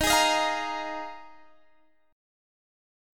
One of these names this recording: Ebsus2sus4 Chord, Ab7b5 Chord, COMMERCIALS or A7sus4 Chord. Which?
Ebsus2sus4 Chord